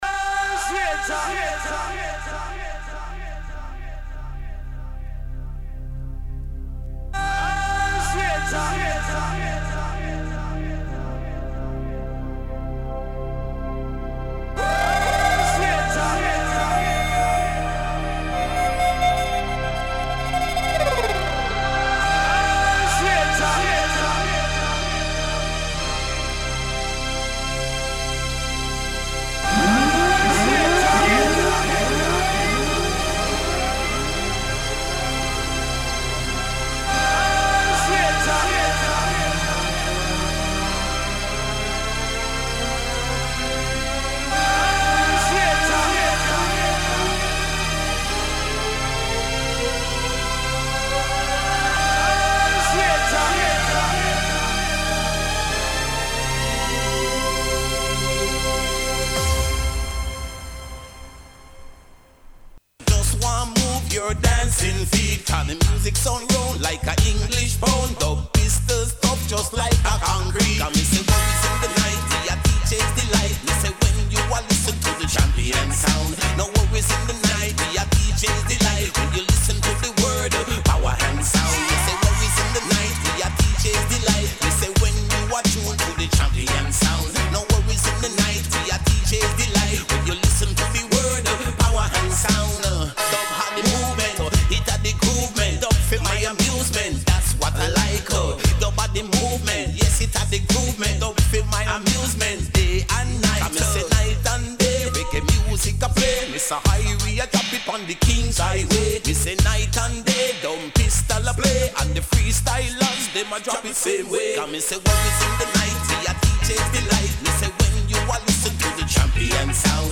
⌂ > Vinyly > Jungle-Drum&Bass >